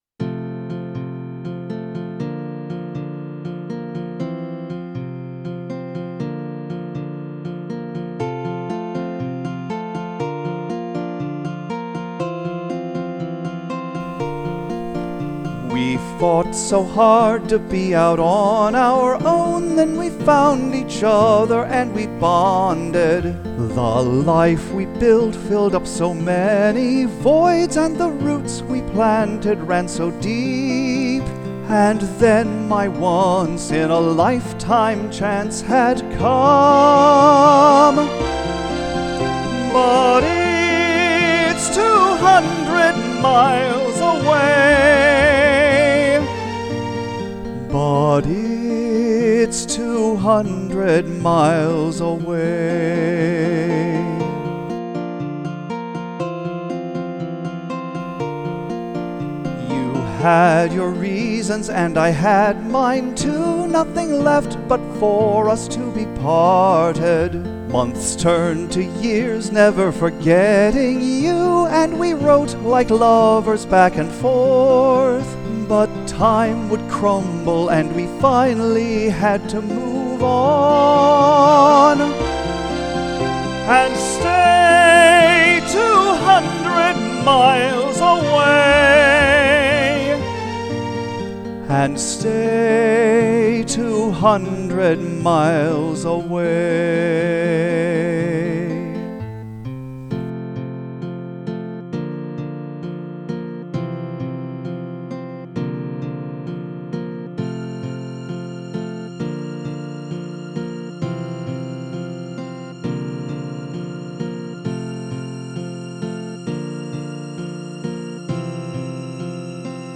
200 Miles Away, southern accent version (.MP3 format)
The melody at the beginning is a variation on "The Minstrel Boy," written by Thomas Moore in 1813.
I recorded two versions: One with my normal voice, and one where I tried to be the guy who sang "Old Man River" and thus used a southern accent.